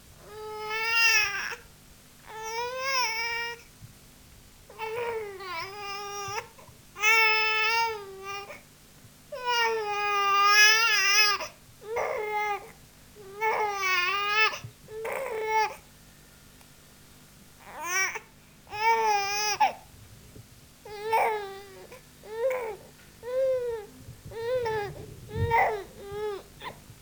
На этой странице собраны различные звуки плача младенцев: от тихого хныканья до громкого крика.
Плач маленького ребенка